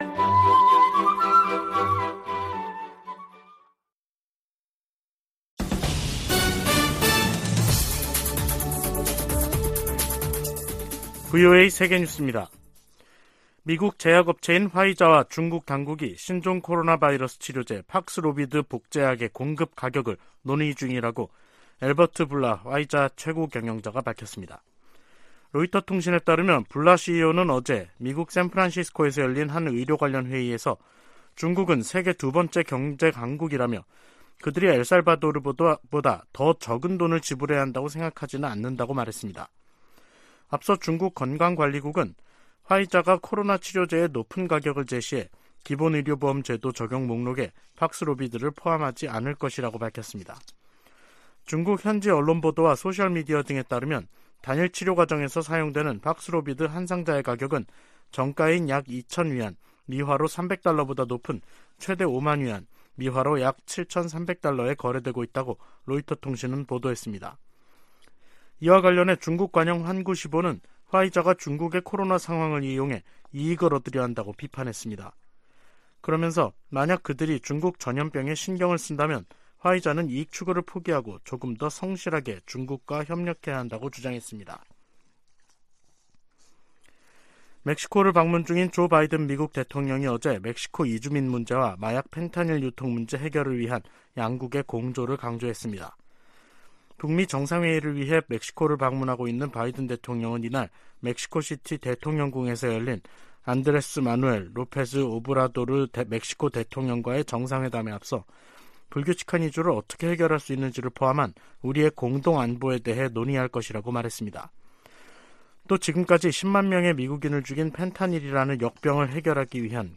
VOA 한국어 간판 뉴스 프로그램 '뉴스 투데이', 2023년 1월 10일 2부 방송입니다. 미 국무부는 방한 중인 국무부 경제 차관이 미국의 인플레이션 감축법(IRA)에 대한 한국의 우려에 관해 논의할 것이라고 밝혔습니다. 한국 군 당국은 대북 확성기 방송 재개 방안을 검토하고 있는 것으로 알려졌습니다.